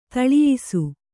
♪ taḷiyisu